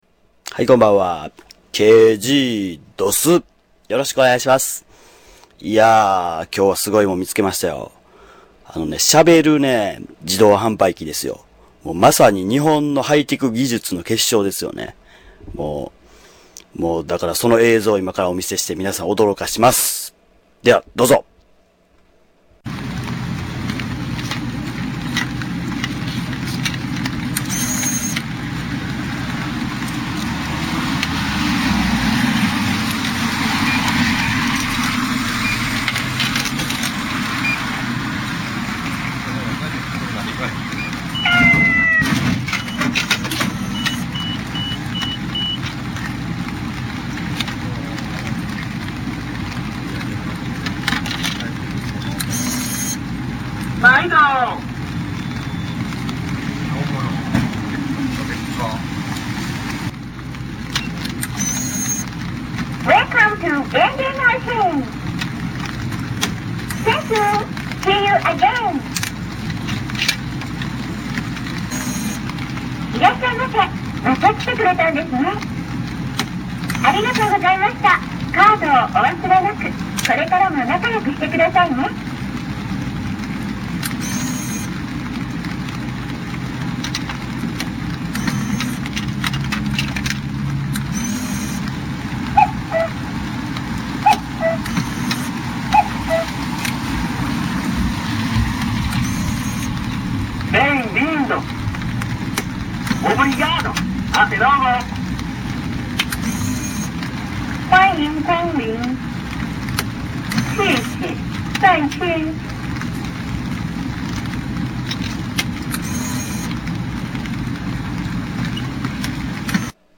Speaking vending machine
Tags: Travel Japan Japanese Accent Japanese accent